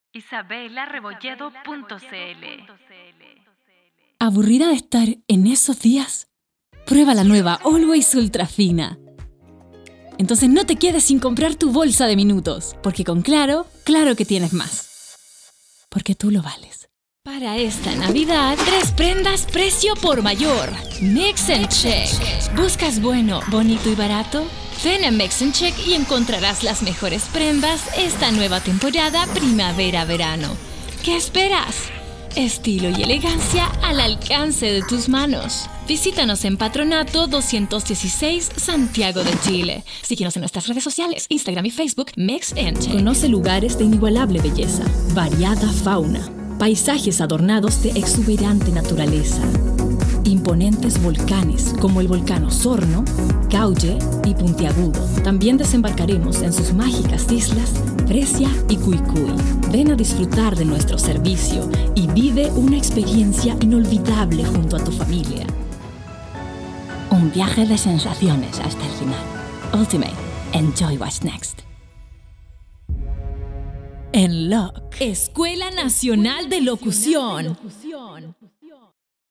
Bilingual Voiceover Artist and Singer-songwriter
NEUTRAL SPANISH, CHILEAN AND CASTILLIAN COMMERCIAL DEMO UPBEAT
demo español comercial marcas y catamaran cn musica listo.wav